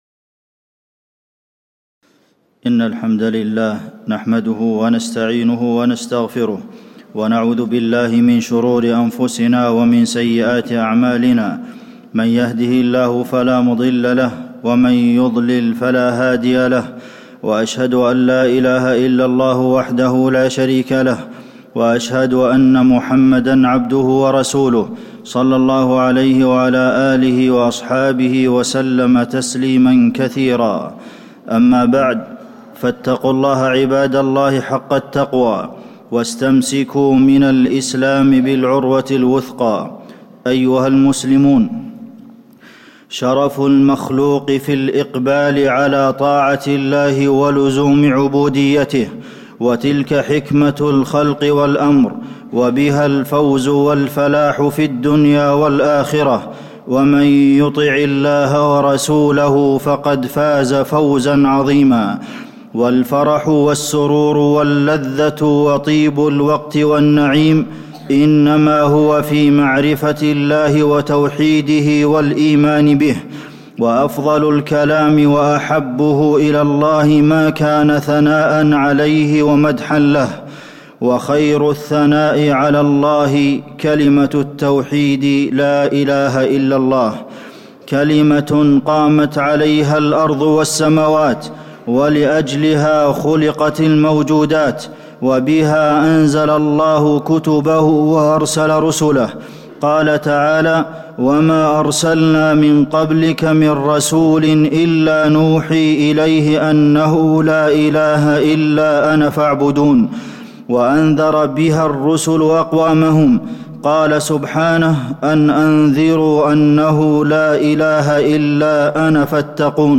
تاريخ النشر ٦ جمادى الأولى ١٤٣٨ هـ المكان: المسجد النبوي الشيخ: فضيلة الشيخ د. عبدالمحسن بن محمد القاسم فضيلة الشيخ د. عبدالمحسن بن محمد القاسم كلمة التوحيد لا إله إلا الله